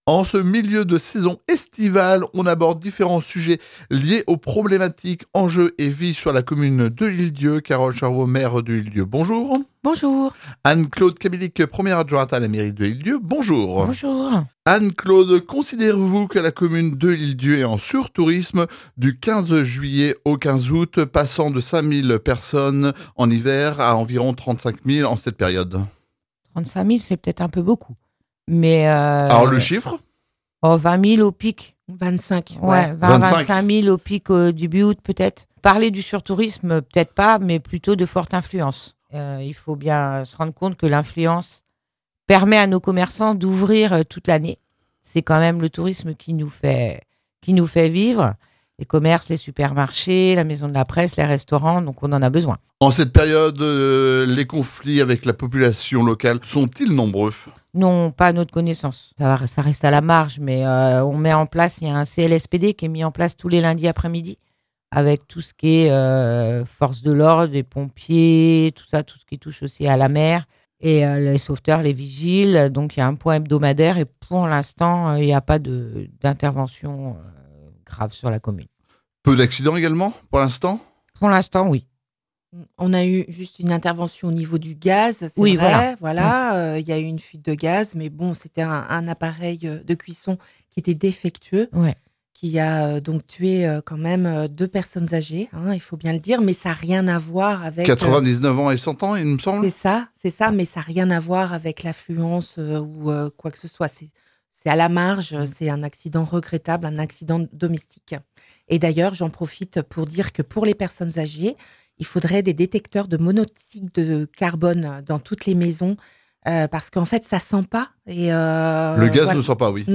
En plein milieu de cette période estivale, Carole Charuau, maire de l'Ile d'Yeu, et Anne-Claude Cabillic, sa première adjointe, sont venues faire un point la vie et les problématiques sur l'île.